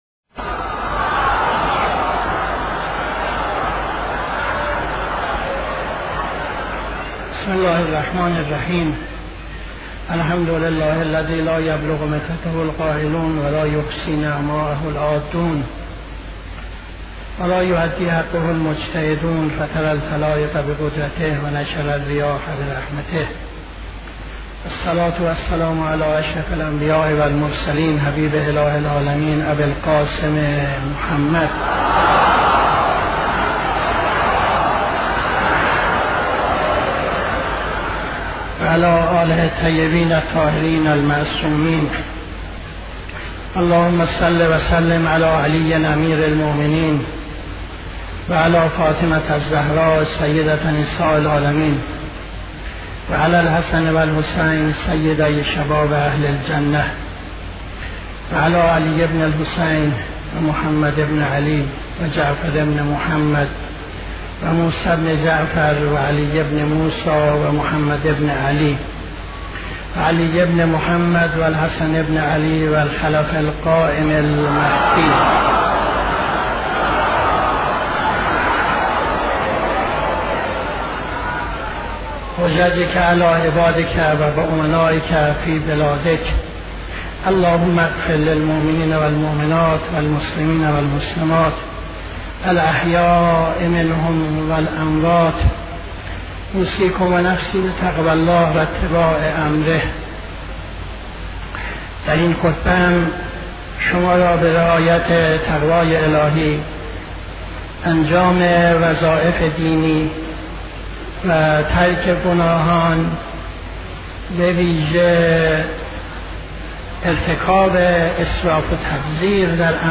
خطبه دوم نماز جمعه 01-12-76